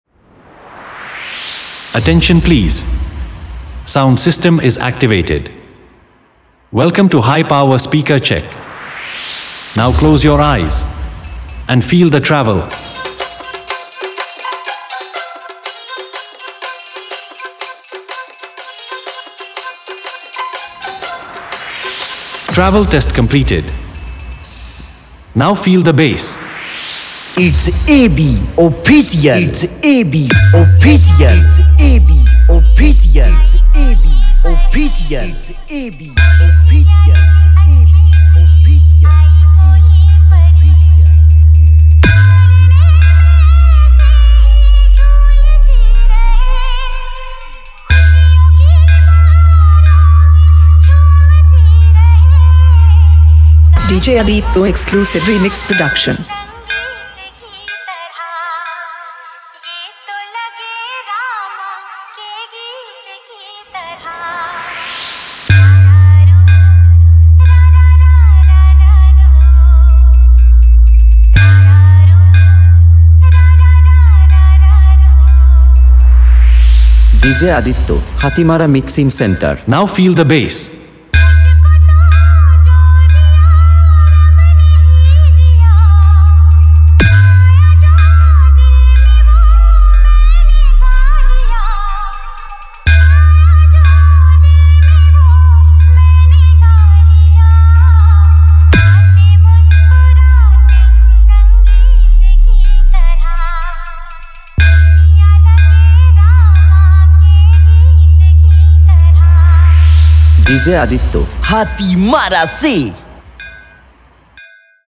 Dj Remixer
Sound Check Dj Remix